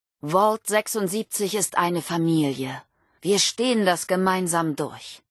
Audiodialoge